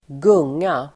Uttal: [²g'ung:a]